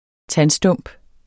Udtale [ ˈtan- ]